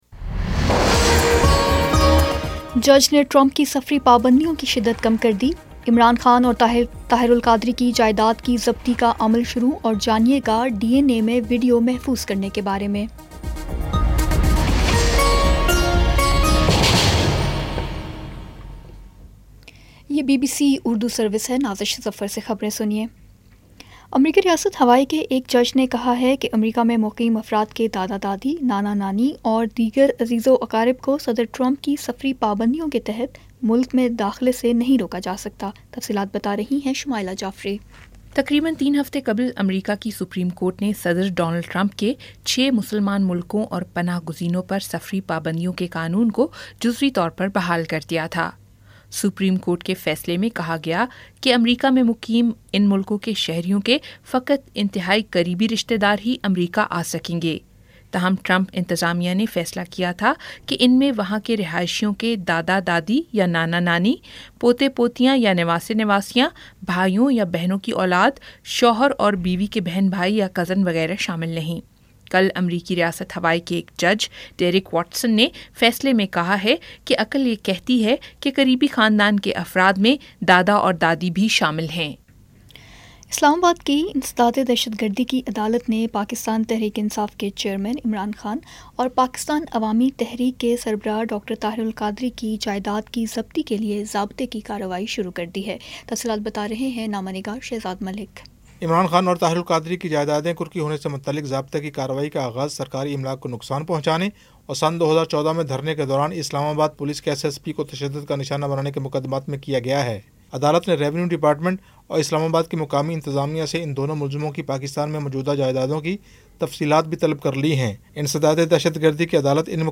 جولائی 14 : شام چھ بجے کا نیوز بُلیٹن